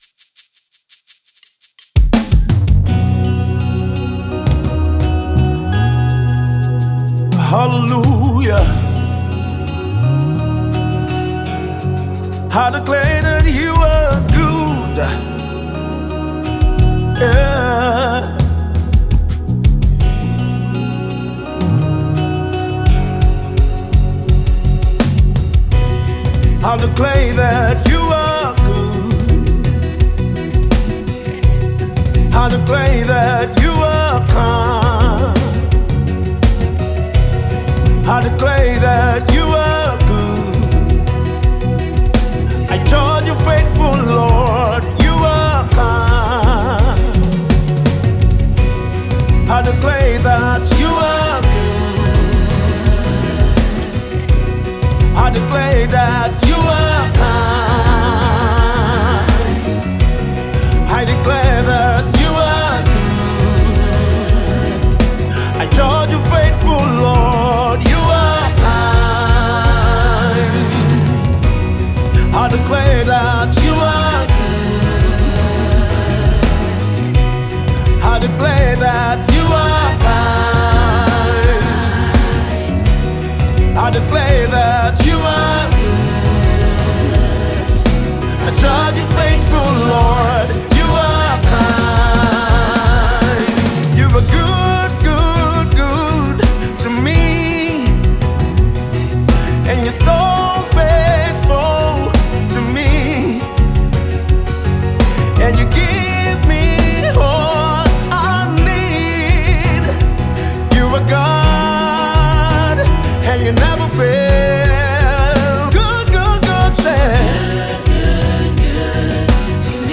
energetic and electrifying praise style